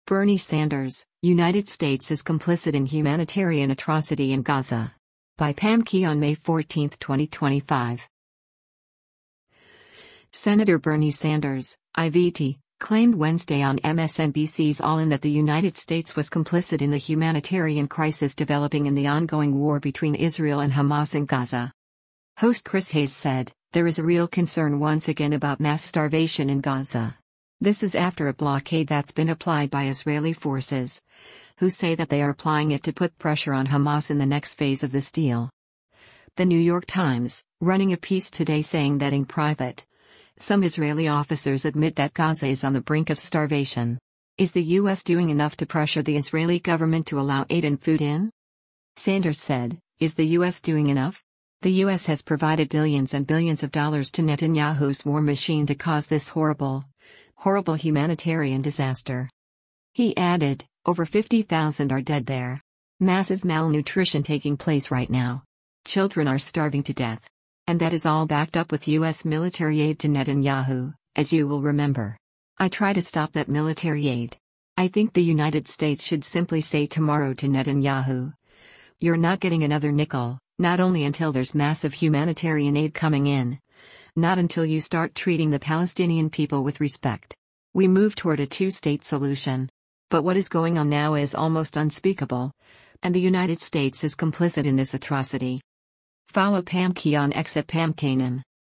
Senator Bernie Sanders (I-VT) claimed Wednesday on MSNBC’s “All In” that the United States was “complicit” in the humanitarian crisis developing in the ongoing war between Israel and Hamas in Gaza.